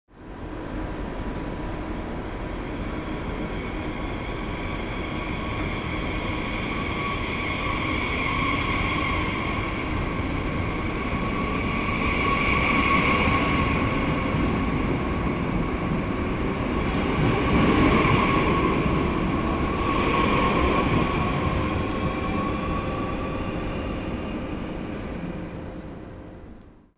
دانلود صدای قطار 14 از ساعد نیوز با لینک مستقیم و کیفیت بالا
جلوه های صوتی